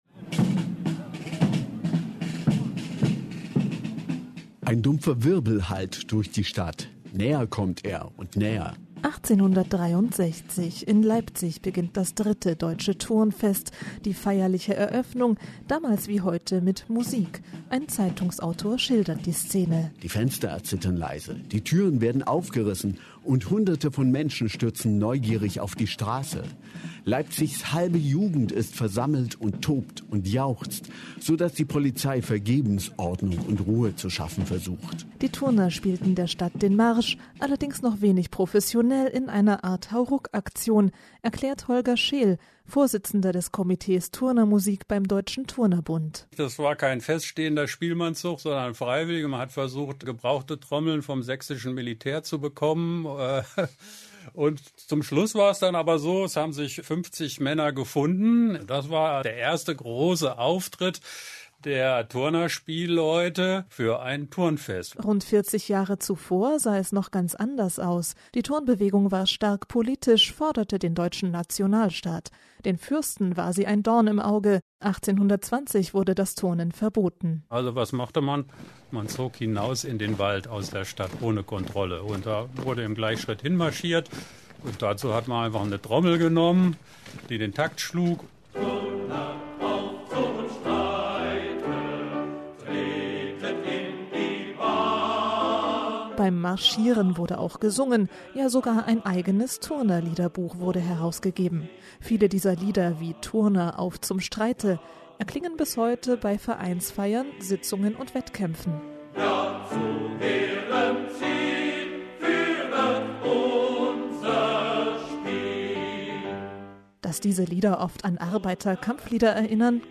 MDR Klassik – Turnen und Musik – Interview